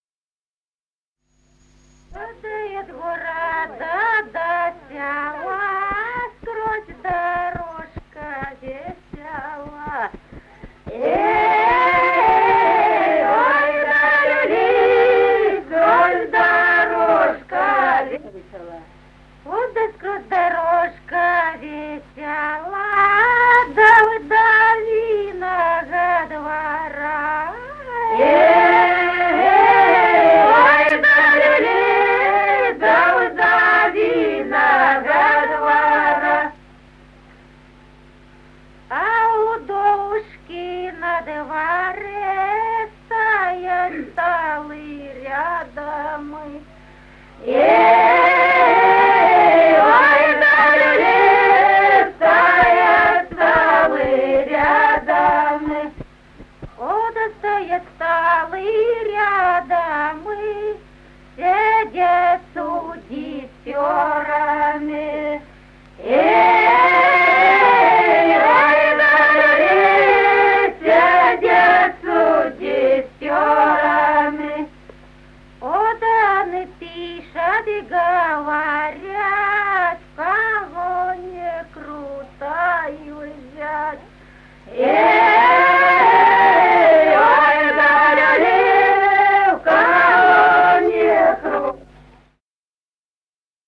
Народные песни Стародубского района «Эх, да от города до села», рекрутская.
(запев)
(подголосник)